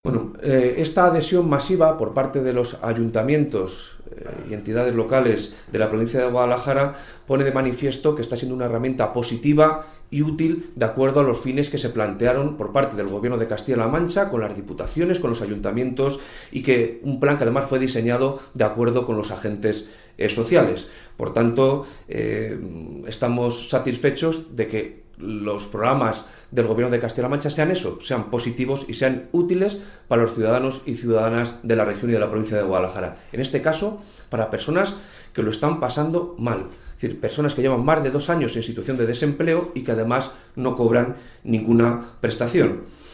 El delegado de la Junta en Guadalajara habla sobre la acogida del Plan de Empleo en la provincia.